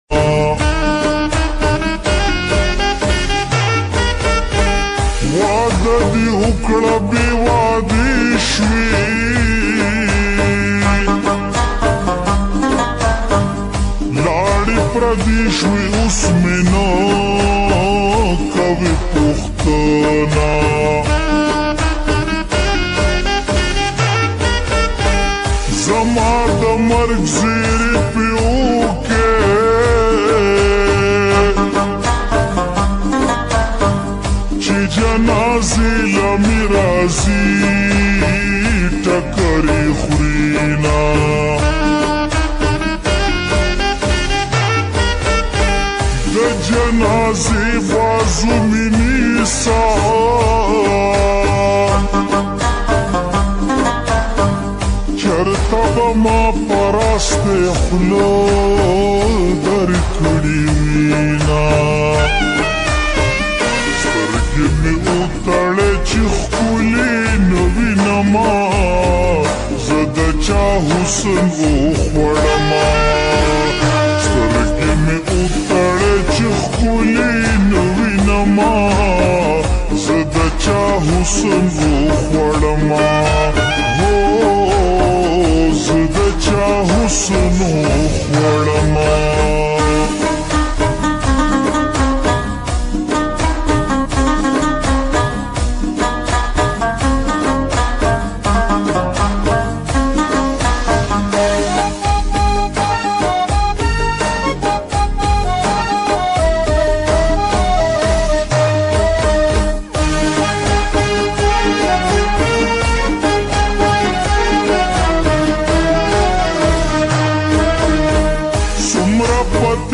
Slowed and Reverb
slowed reverb song